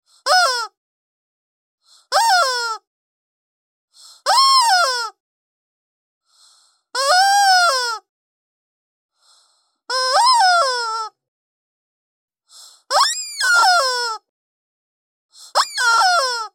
دانلود صدای خروس عروسکی جالب و خنده دار از ساعد نیوز با لینک مستقیم و کیفیت بالا
جلوه های صوتی